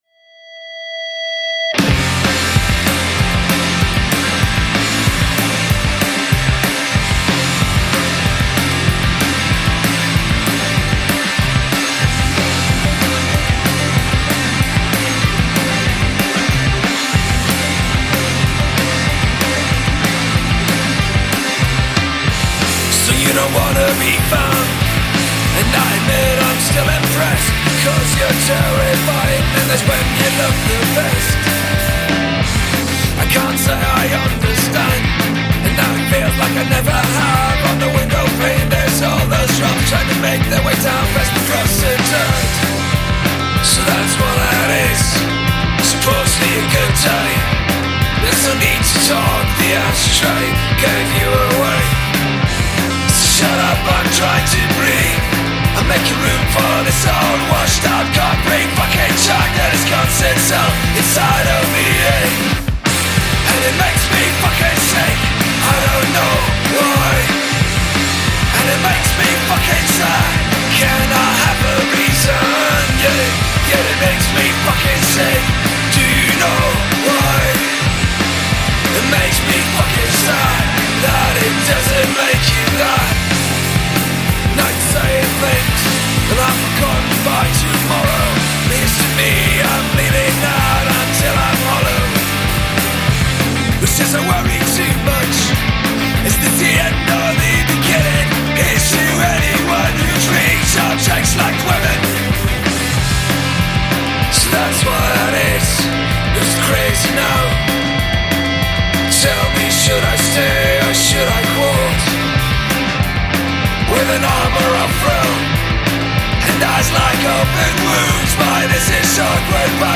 Cologne Germany punk four-piece